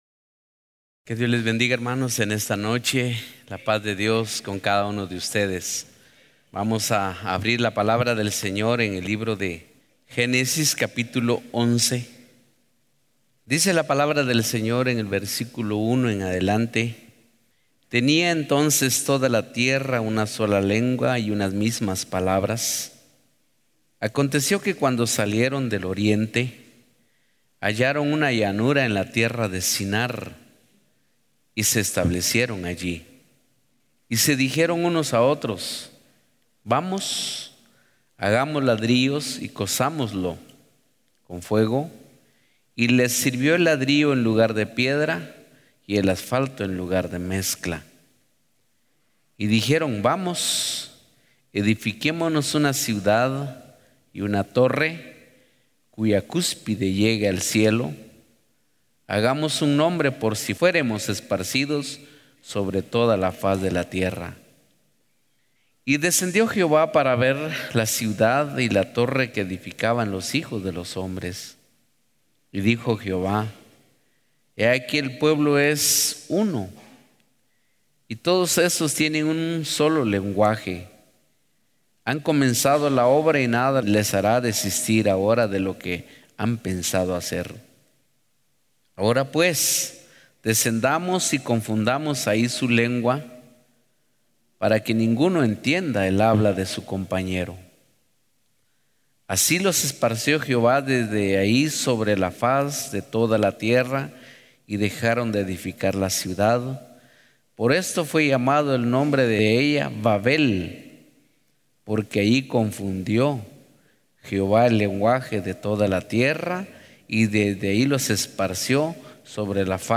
Series: Servicio General